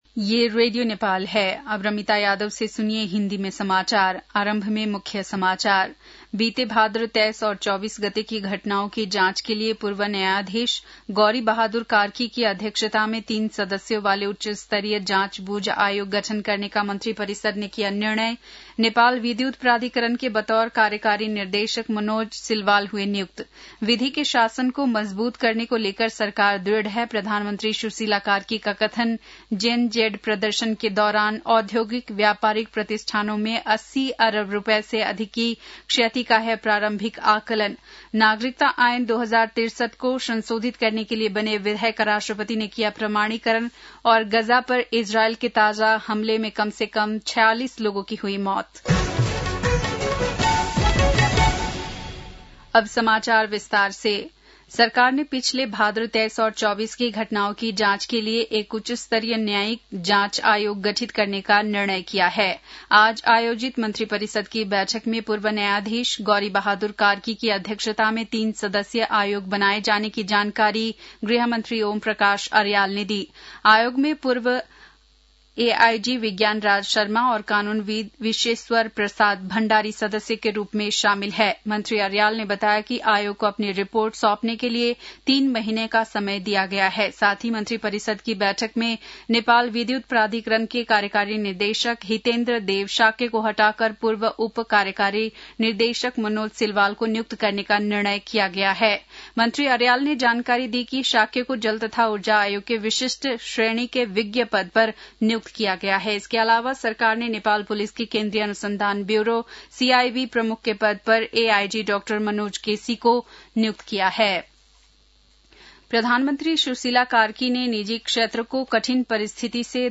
बेलुकी १० बजेको हिन्दी समाचार : ५ असोज , २०८२
10-pm-Hindi-news-.mp3